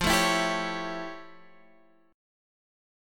Listen to F+M9 strummed